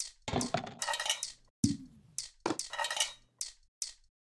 标签： 寒意 循环 环境 即兴音乐 打击乐循环 歌曲
声道立体声